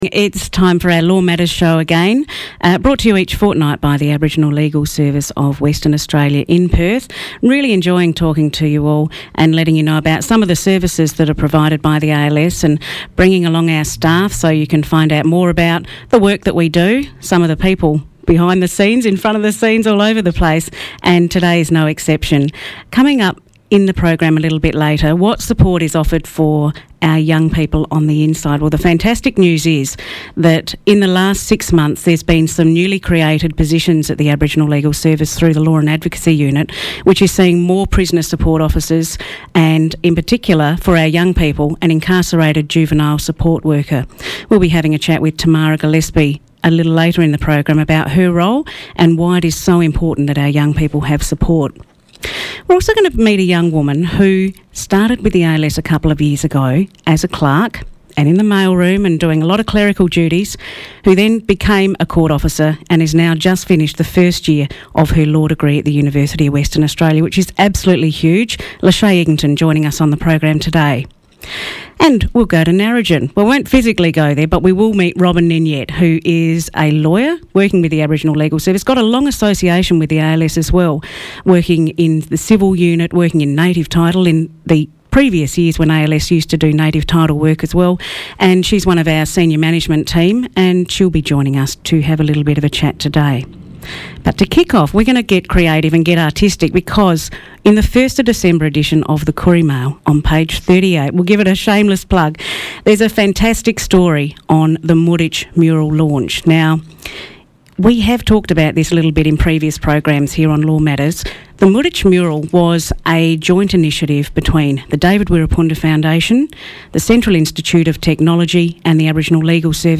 Law Matters is produced and presented by the Aboriginal Legal Service of WA (ALSWA).